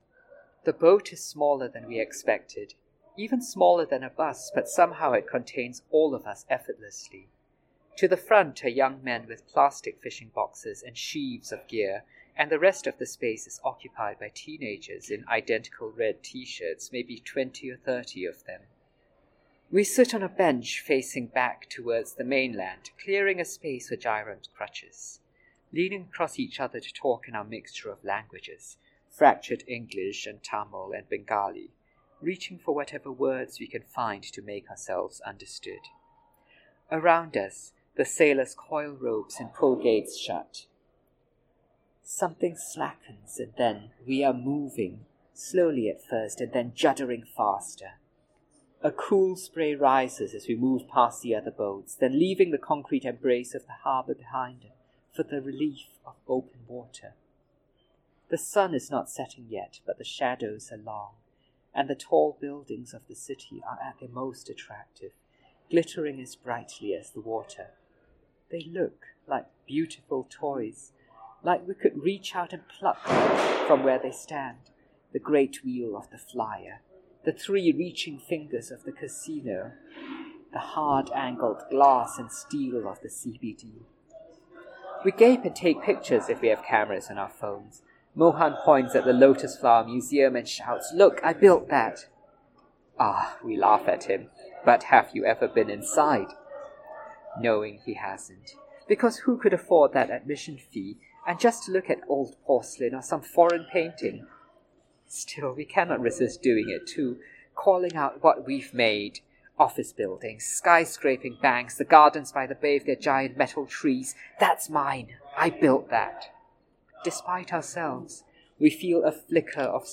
Reading An Excerpt